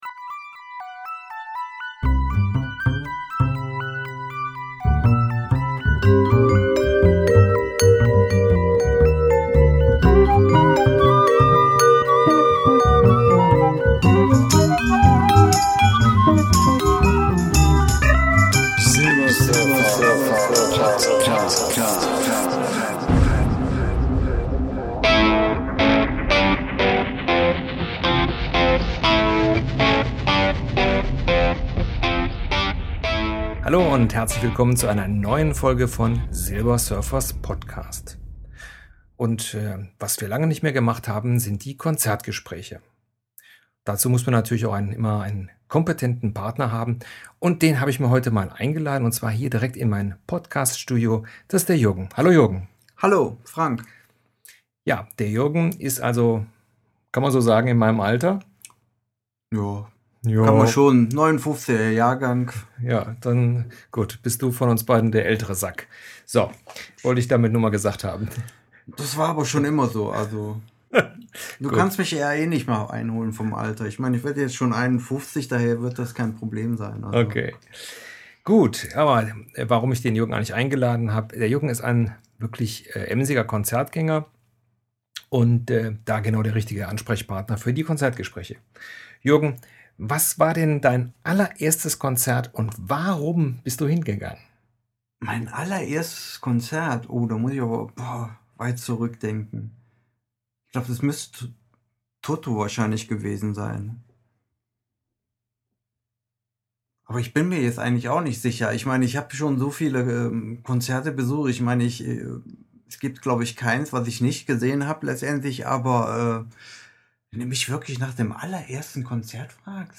Tags:Konzert, Erinnerungen, Zeitreise, Interview, Musik, Rock
Zur Ausgabe Nr.4 der Konzertgespräche hab ich mir einen Gast in das Podcaststudio eingeladen.